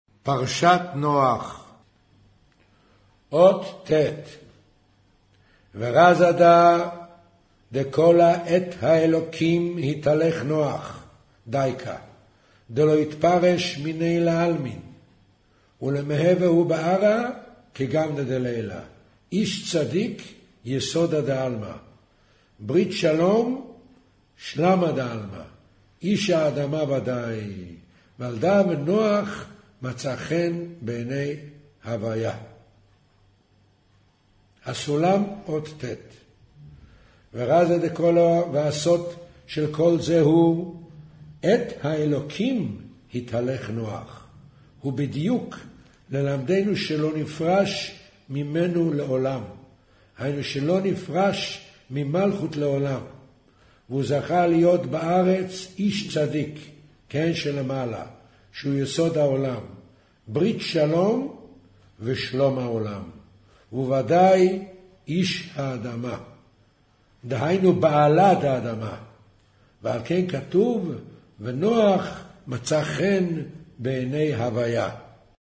אודיו - קריינות זהר, פרשת נח, מאמר נח ותיבה אות ט'